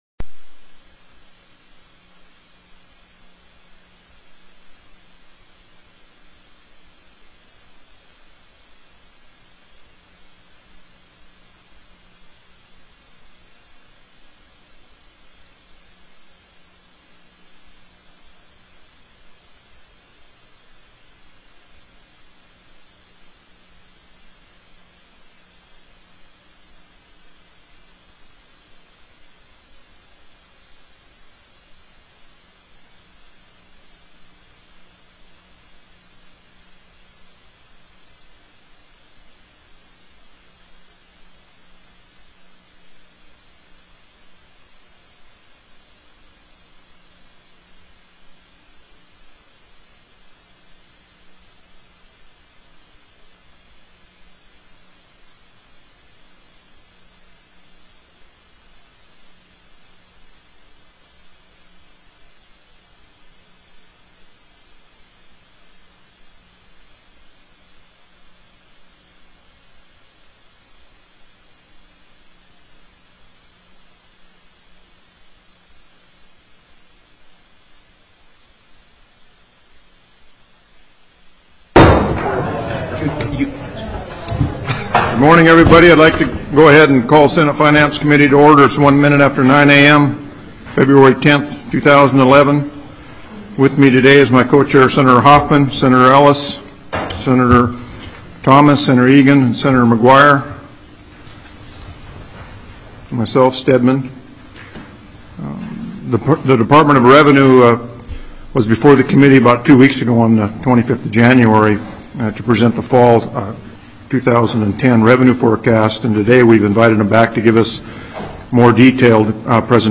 Presentation on Oil and Gas Tax Credits by the Department of Revenue
TELECONFERENCED